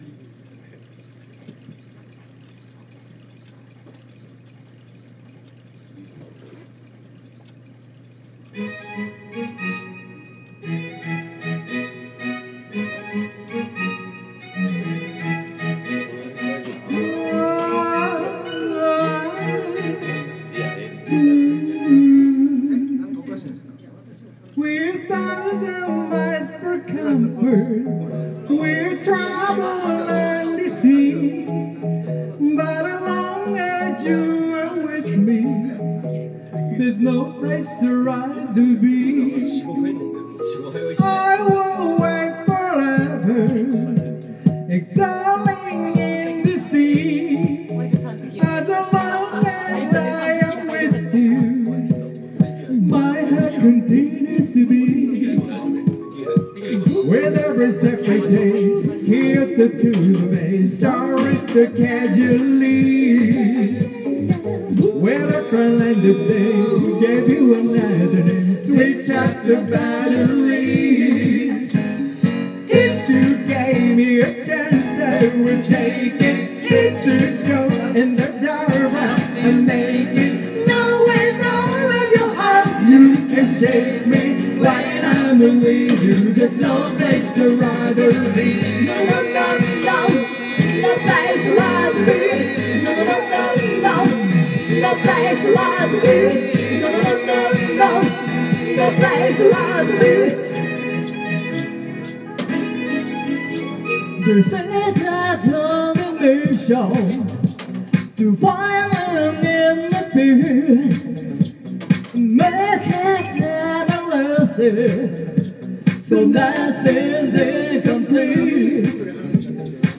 Duet & Chorus Night Vol. 12 TURN TABLE